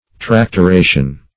Tractoration \Trac`to*ra"tion\, n.